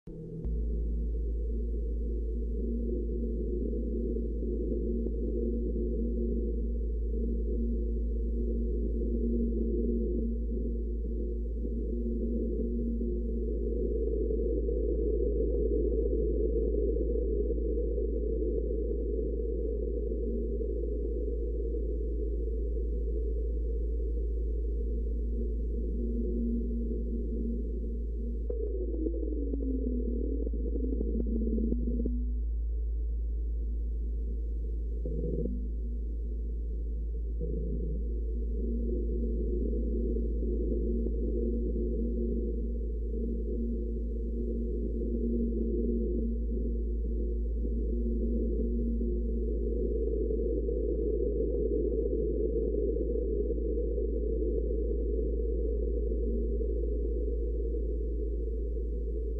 Frequência Planeta marte binaural sound effects free download